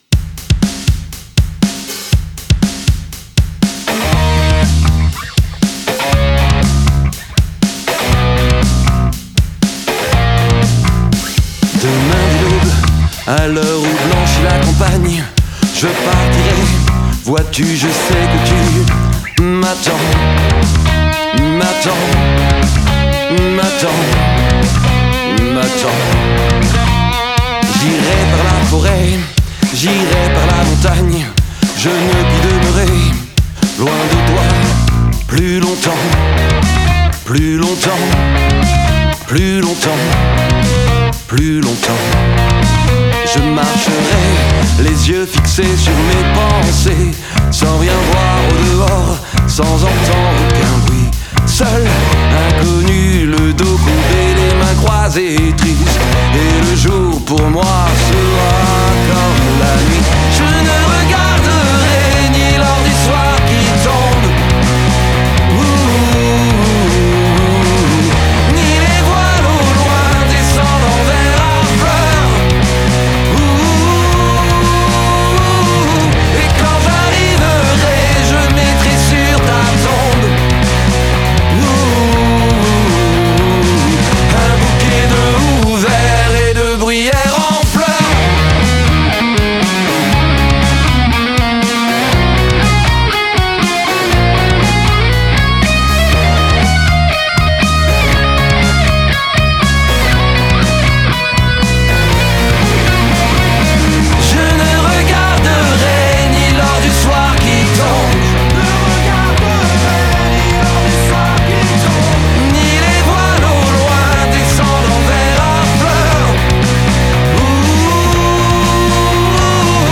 Rock francophone